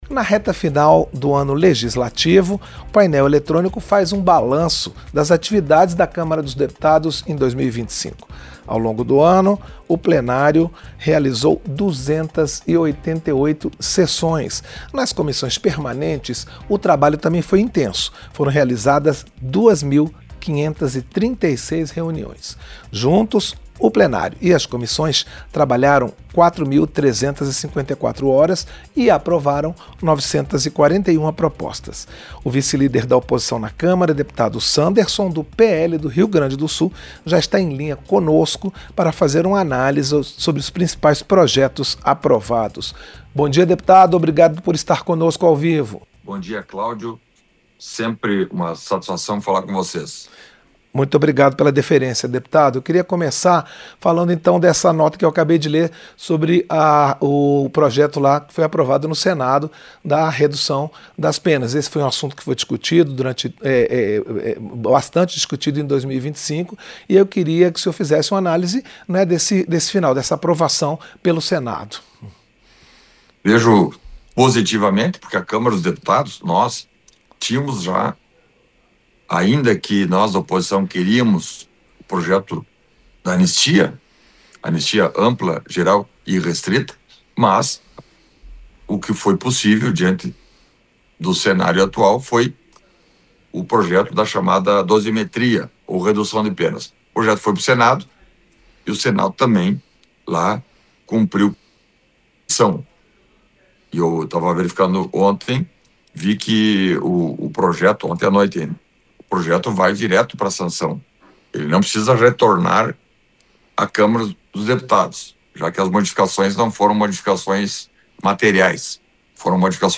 Entrevista -Dep. Sanderson (PL-RS)